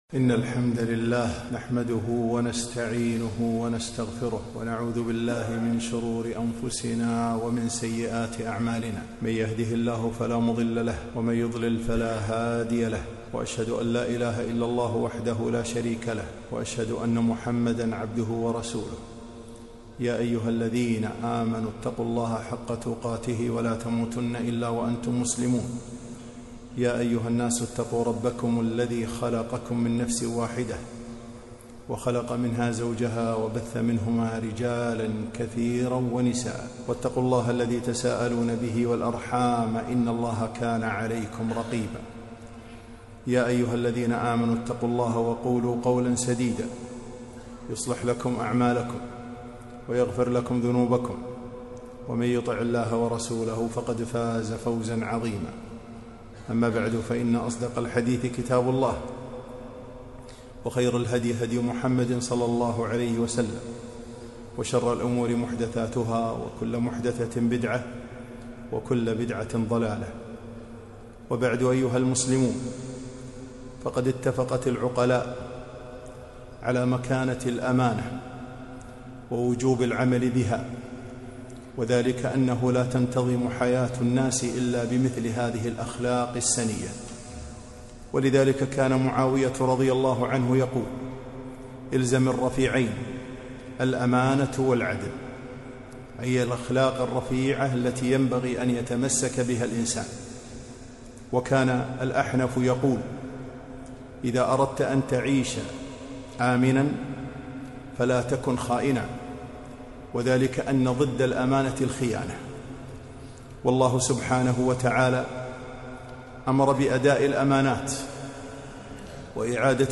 خطبة - الأمانة